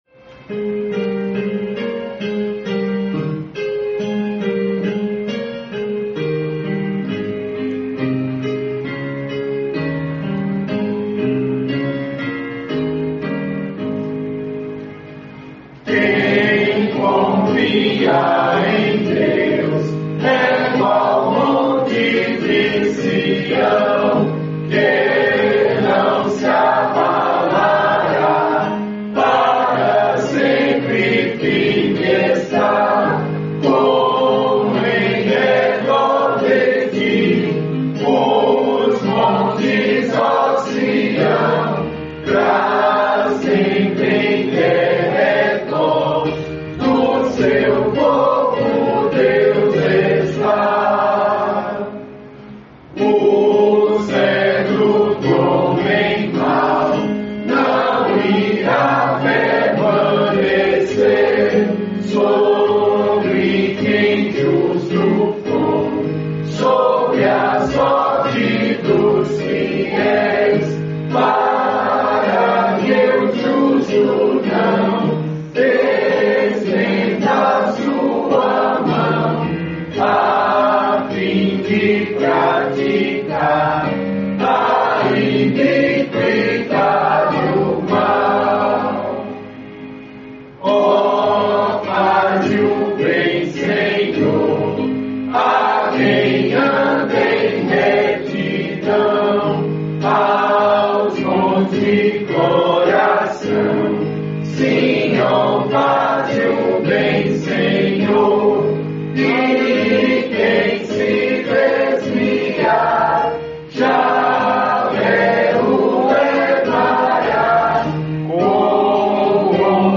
Melodia tradicional espanhola
salmo_125B_cantado.mp3